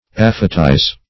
Search Result for " aphetize" : The Collaborative International Dictionary of English v.0.48: Aphetize \Aph"e*tize\, v. t. To shorten by aphesis.